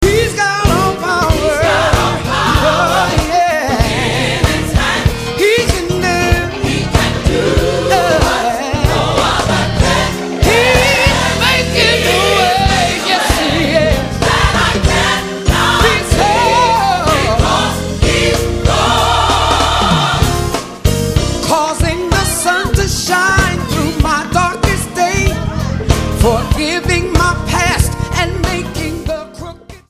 STYLE: Gospel
urged on by an appreciative audience.